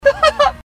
Laugh 21